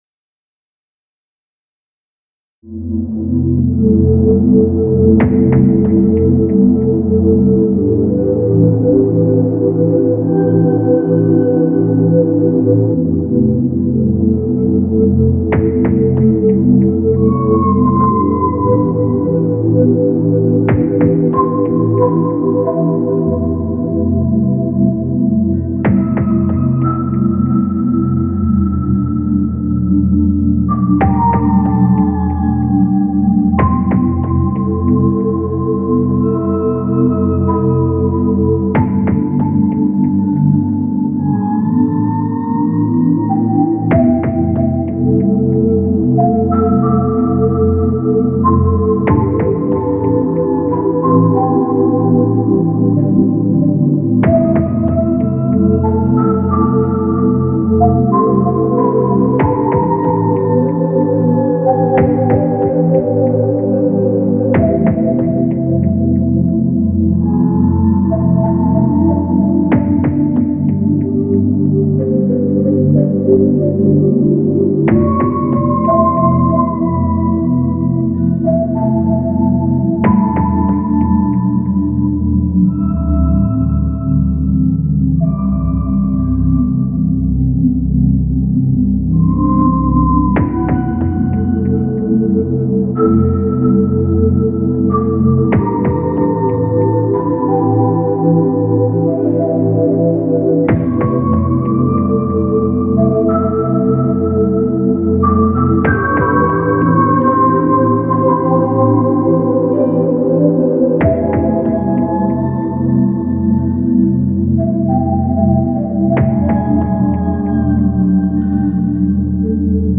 深海をイメージした曲
国産VAシンセ「synth1」だけでがんばろうとして途中で挫折